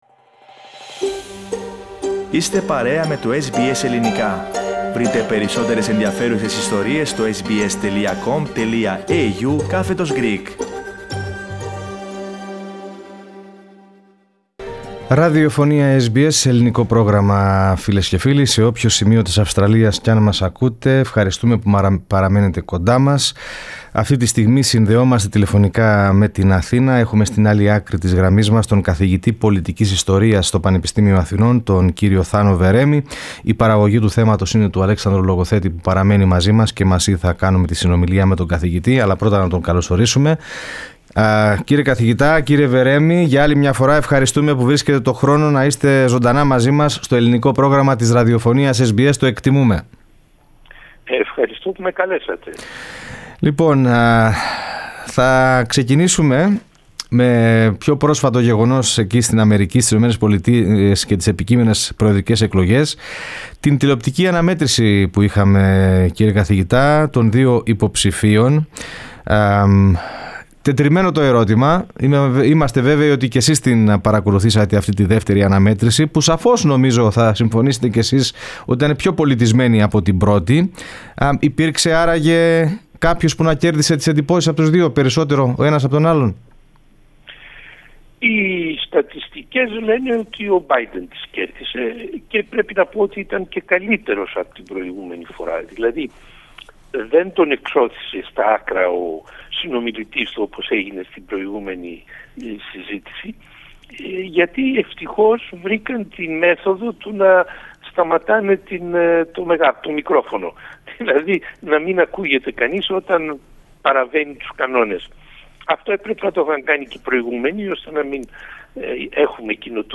Την εκτίμηση, ότι ο Τζο Μπάιντεν, θα είναι ο νικητής των προσεχών προεδρικών εκλογών, εξέφρασε στο Ελληνικό Πρόγραμμα της ραδιοφωνίας SBS, ο καθηγητής Πολιτικής Ιστορίας του Πανεπιστημίου Αθηνών, Θάνος Βερέμης.